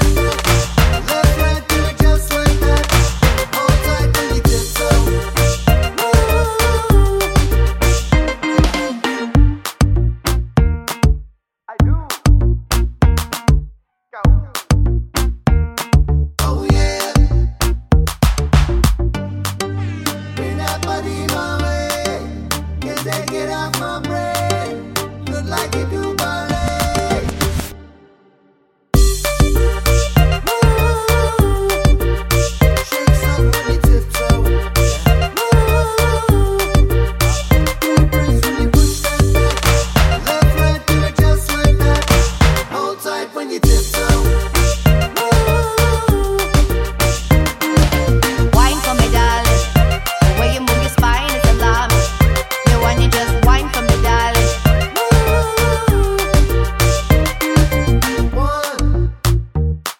for solo male R'n'B / Hip Hop 3:12 Buy £1.50